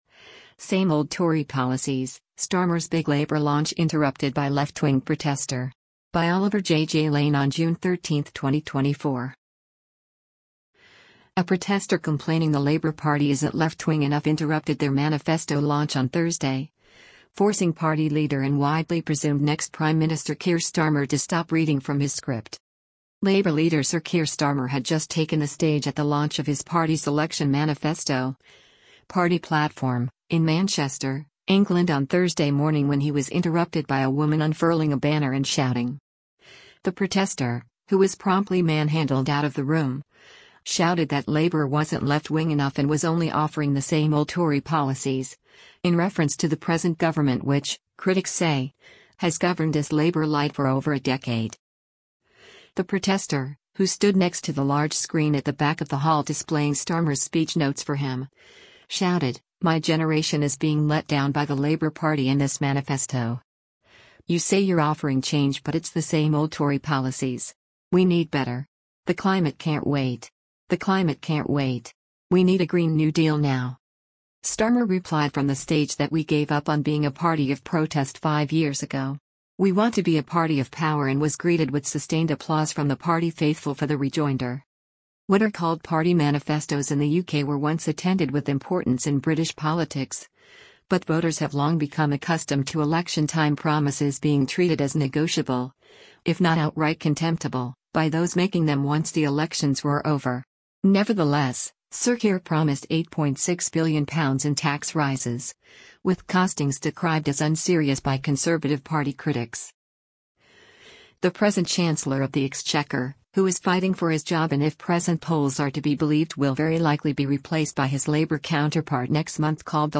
Labour Leader Sir Keir Starmer had just taken the stage at the launch of his party’s election manifesto (party platform) in Manchester, England on Thursday morning when he was interrupted by a woman unfurling a banner and shouting.
Starmer replied from the stage that “we gave up on being a party of protest five years ago. We want to be a party of power” and was greeted with sustained applause from the party faithful for the rejoinder.